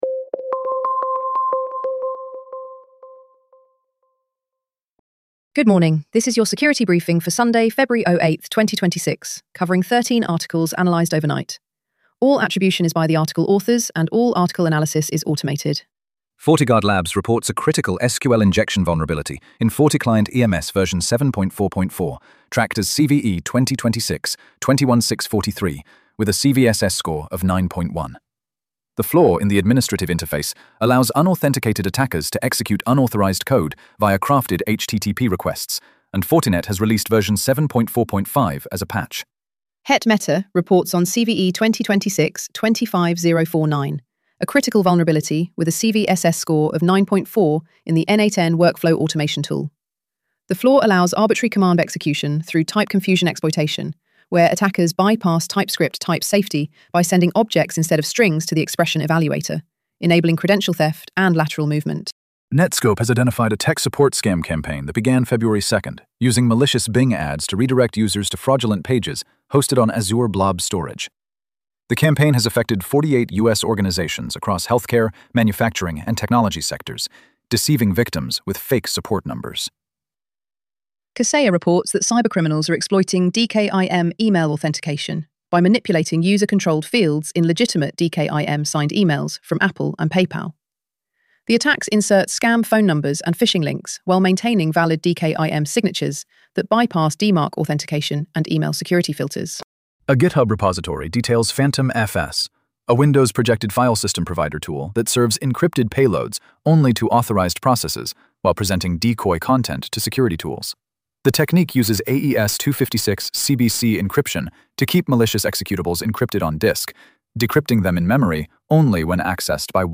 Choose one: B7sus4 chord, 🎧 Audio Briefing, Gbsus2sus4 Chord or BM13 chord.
🎧 Audio Briefing